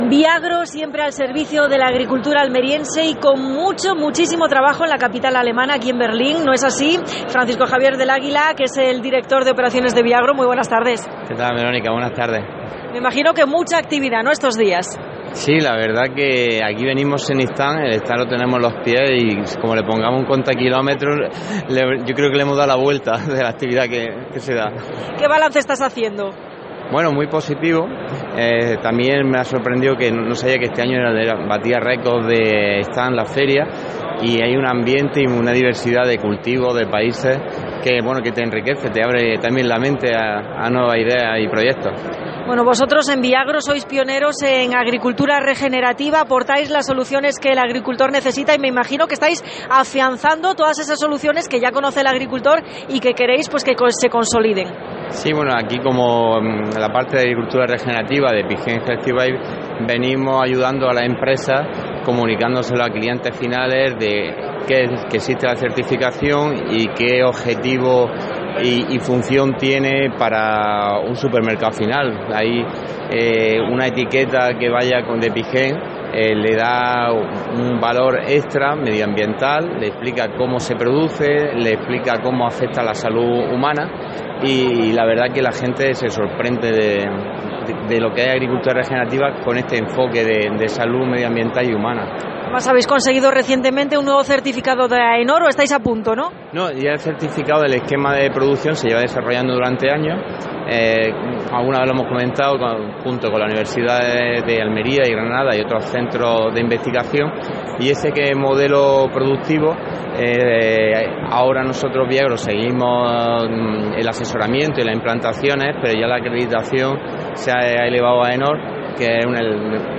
AUDIO: Especial COPE Almería desde Fruit Logística (Berlín).